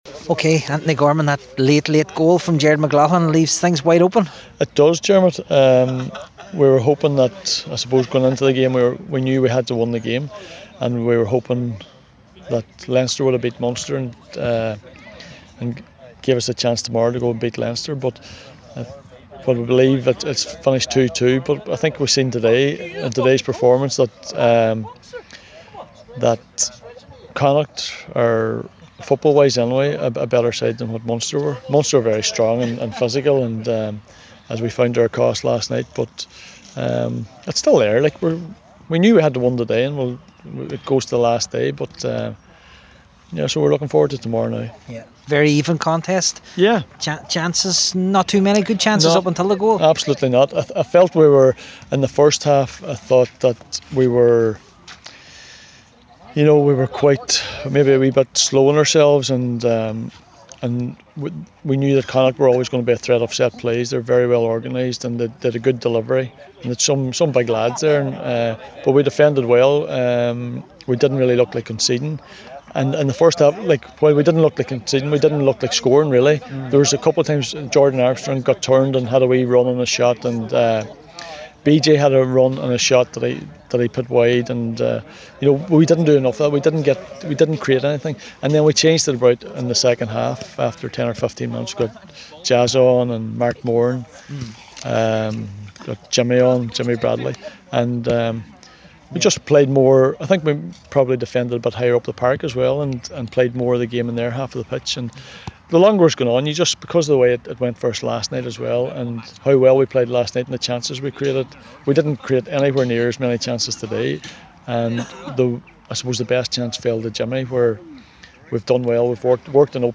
0610-USL-Connacht-Reaction.mp3